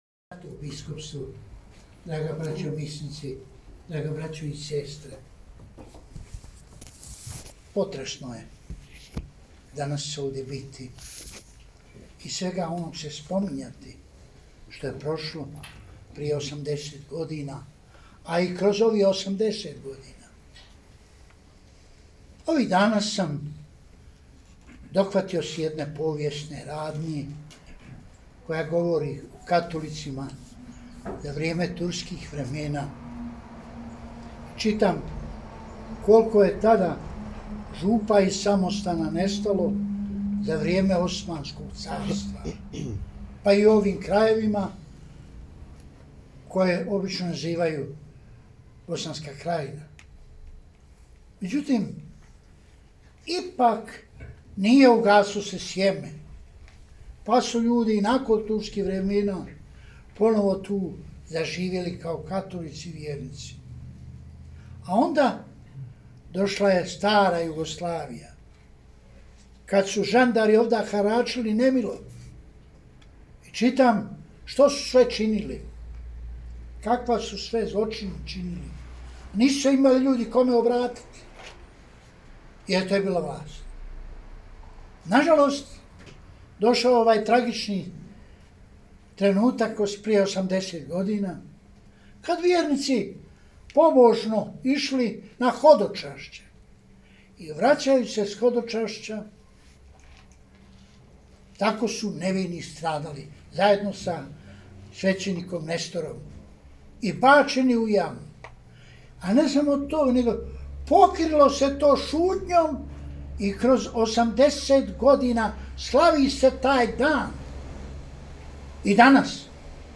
Kardinalova propovijed na misi XX. Dana molitvenog sjećanja na mučenike i žrtve Banjolučke biskupije